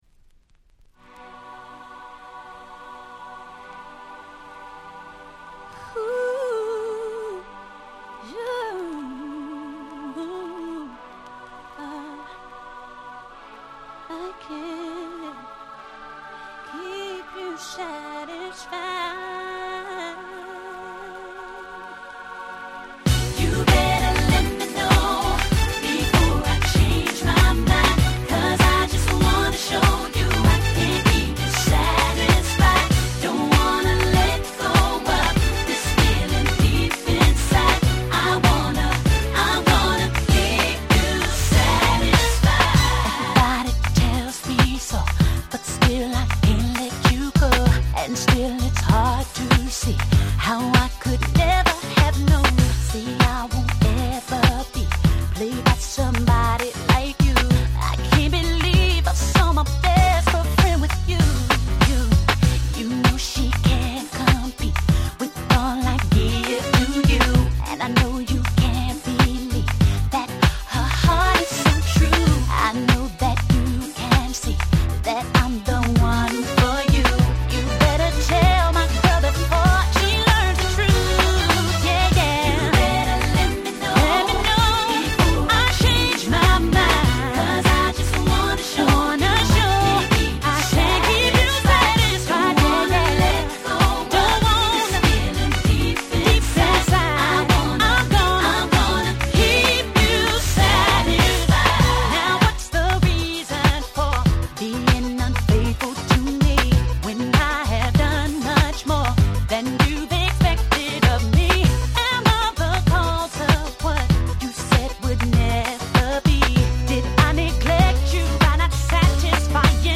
01' Nice R&B !!